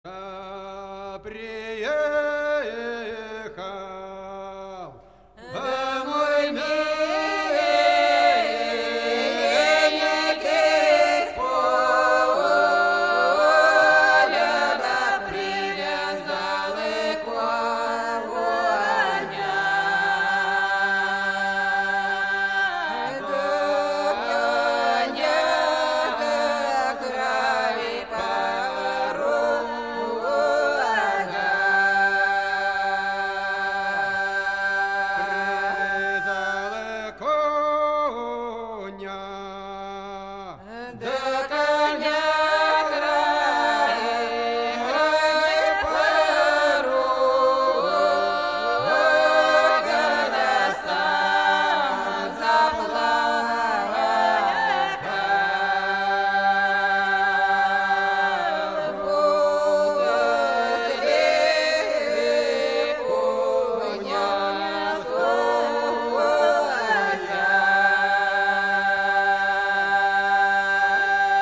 Kursk reg. lingering song